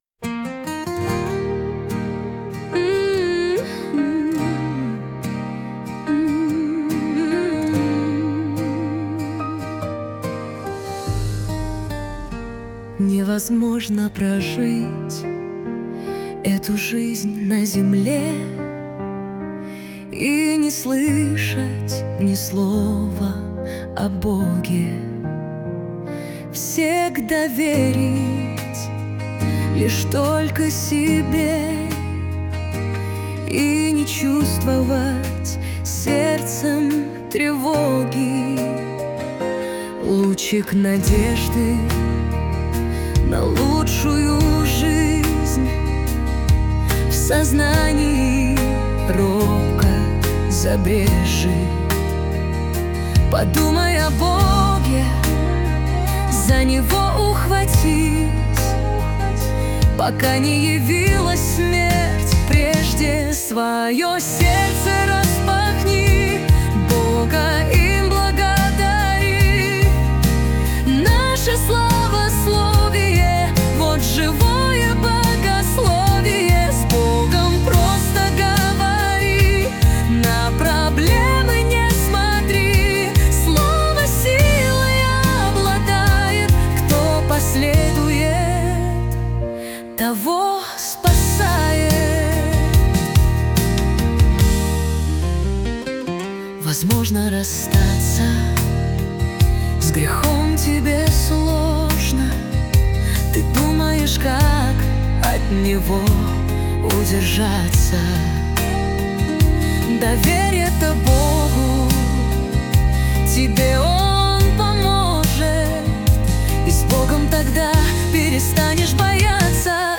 песня ai
220 просмотров 898 прослушиваний 61 скачиваний BPM: 72